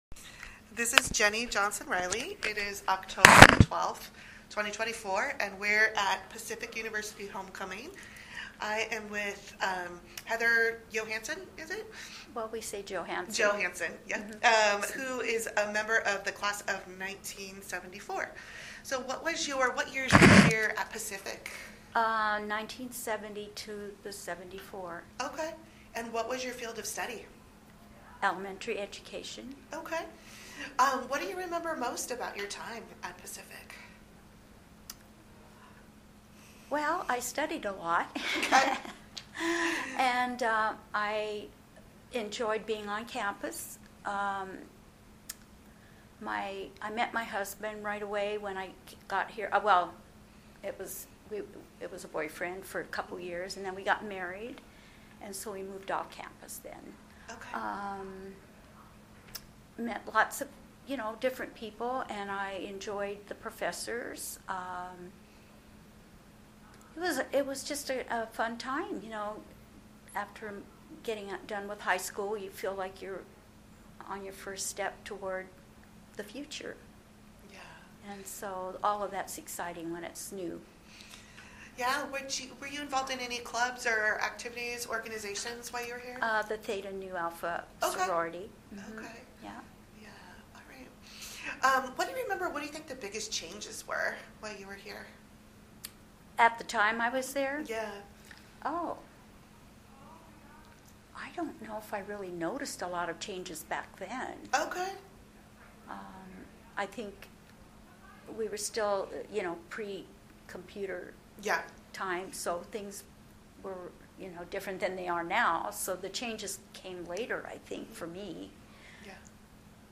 This is one of a group of recordings made during a reunion in October, 2024.